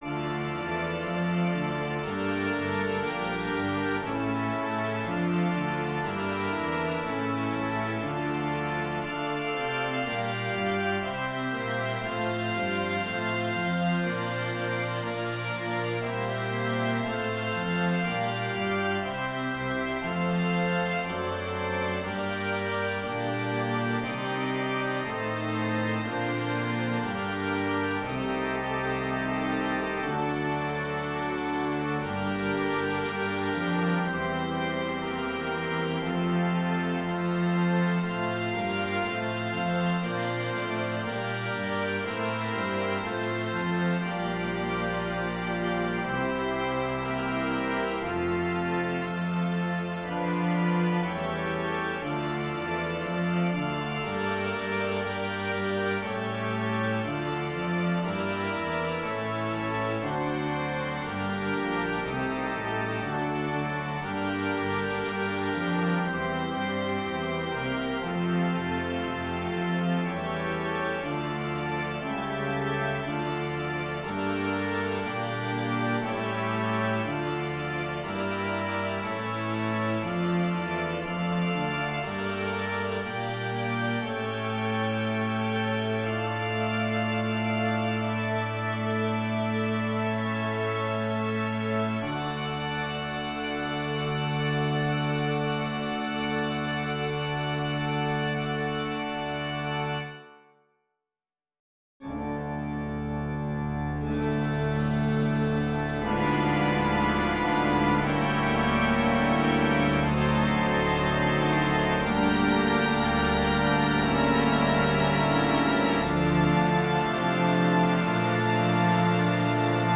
Prelude-Fugue-Strings-Organ.wav